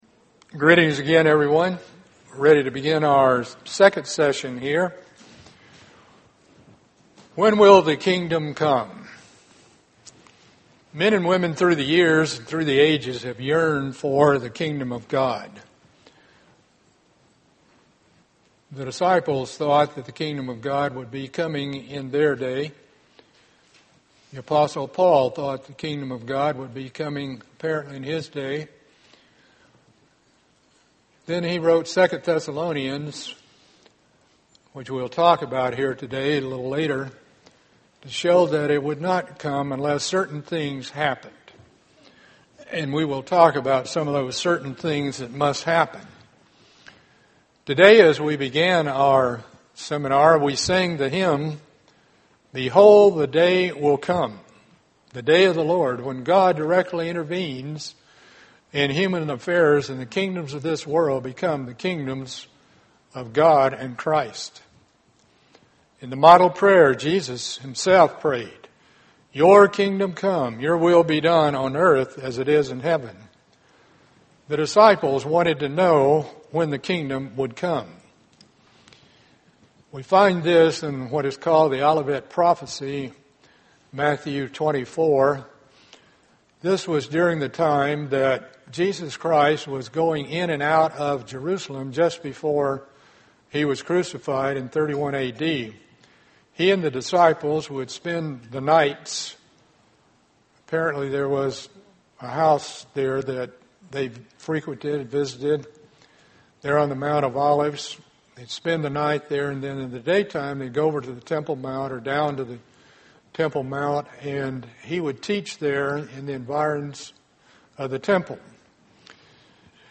Just when will the Kingdom come? Learn more in this Kingdom of God seminar.